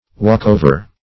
Walk-over \Walk"-o`ver\, n.